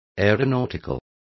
Complete with pronunciation of the translation of aeronautical.